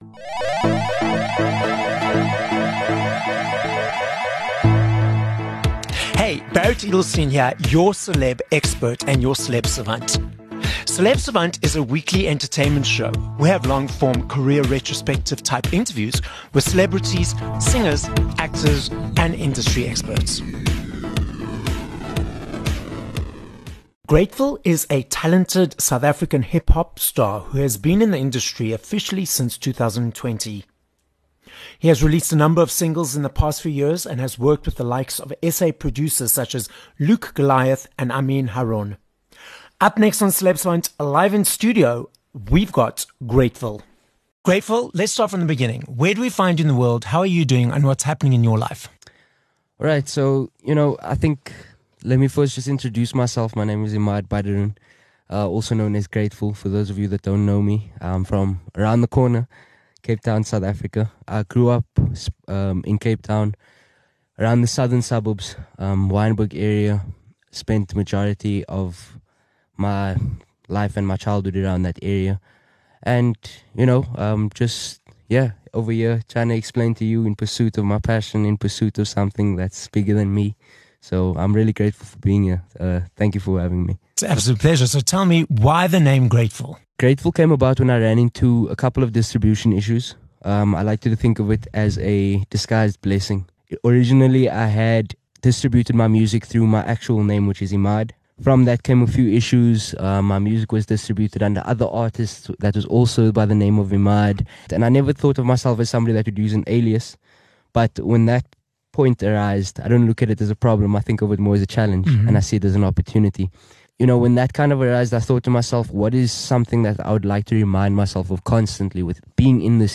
Interview
live in studio.